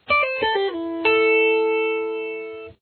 Below are some examples of licks to play that cross over from pattern to pattern using primarily the pentatonic minor lead pattern.